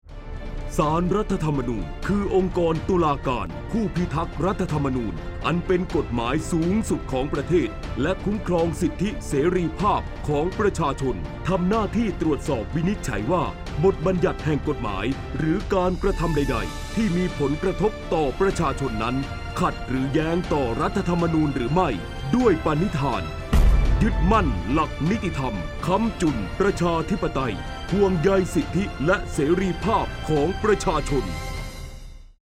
สปอตวิทยุประชาสัมพันธ์ศาลรัฐธรรมนูญ